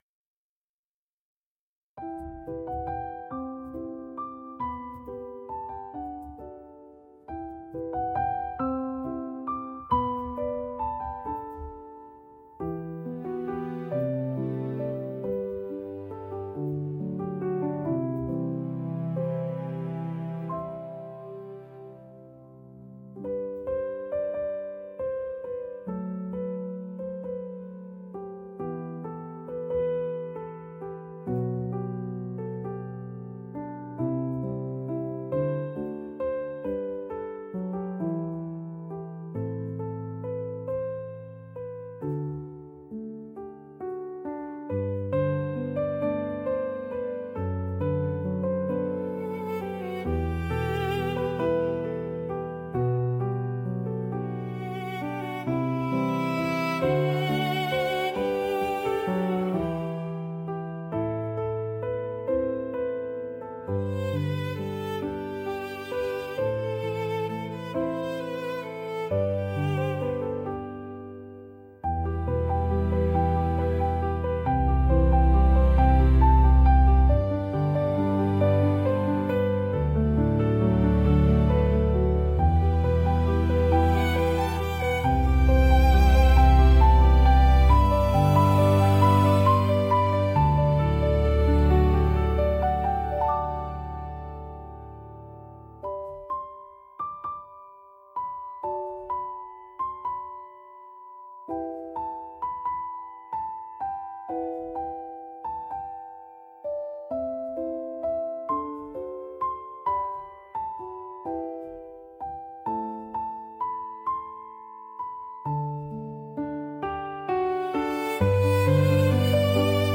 嬉しいジャンルは、明るく陽気な楽曲で、軽快なリズムとポップなメロディが特徴です。
明るさと元気さで、場の雰囲気を一気に盛り上げ、聴く人にポジティブな感情を喚起します。